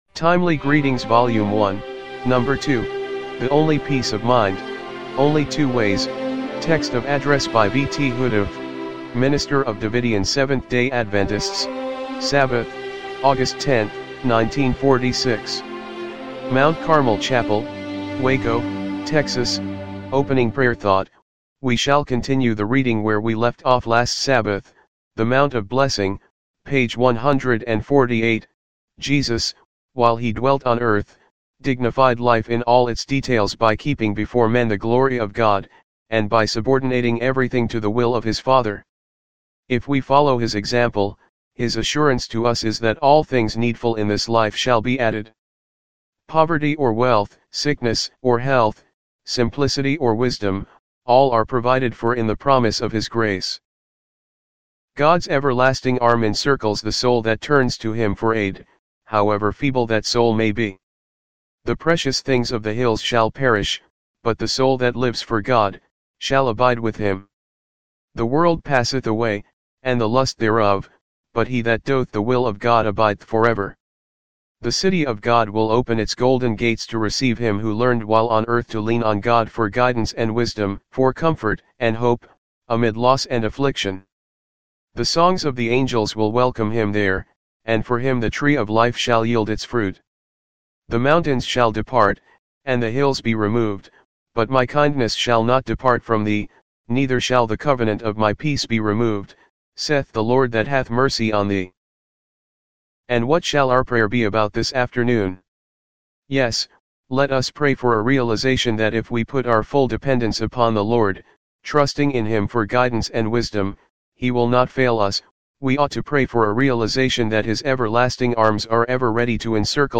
timely-greetings-volume-1-no.-2-mono-mp3.mp3